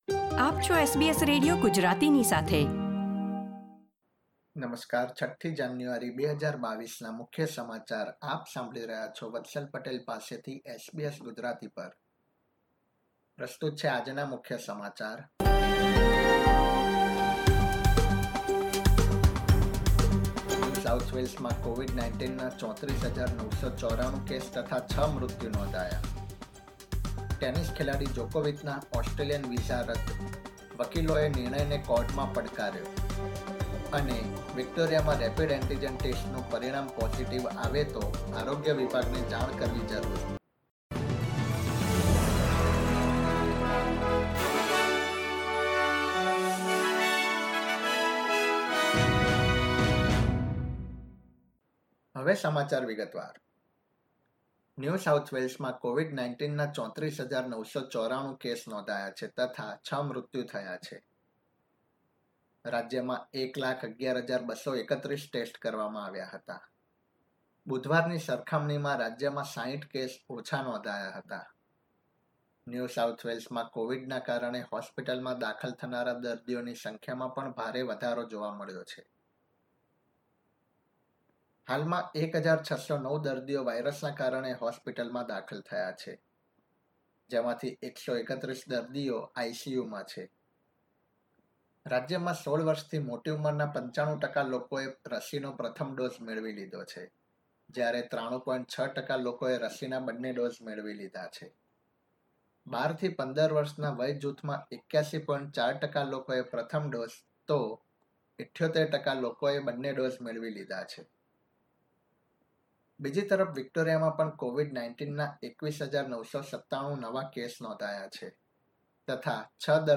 SBS Gujarati News Bulletin 6 January 2022